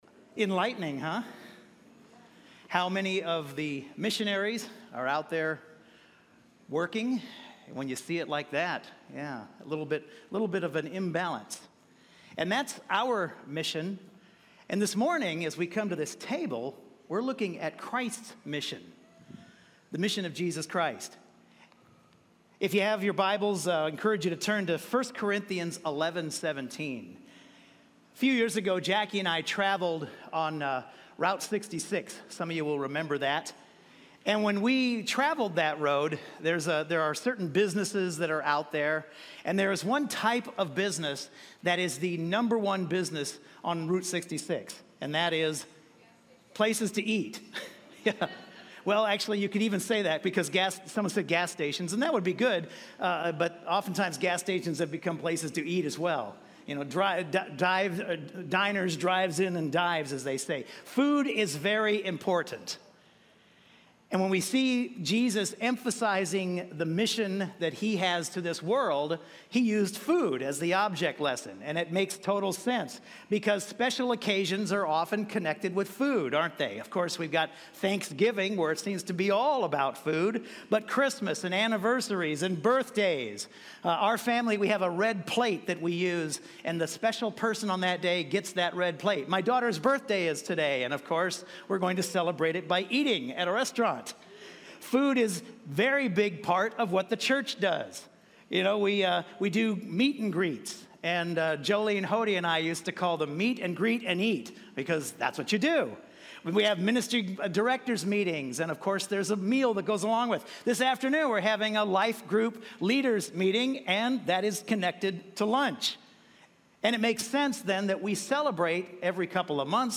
Missions Morning - Communion Service